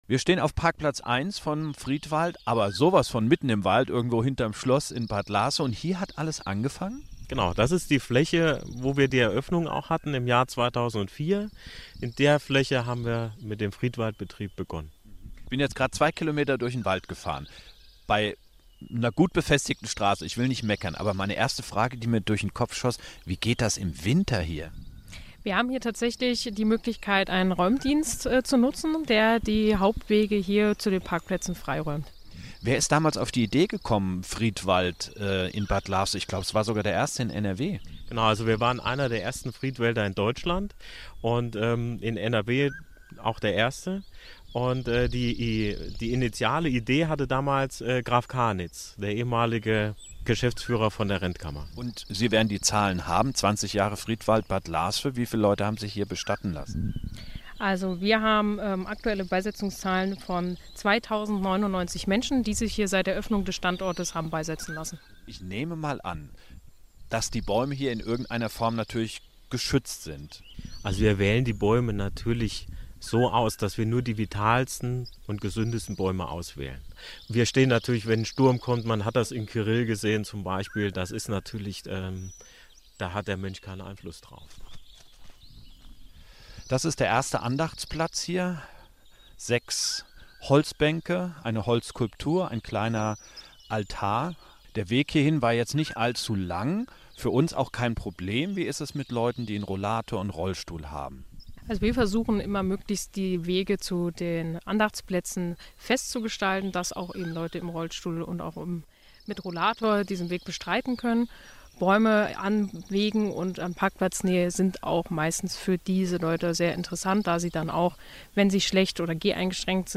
Hier gibt es das extralange Interview.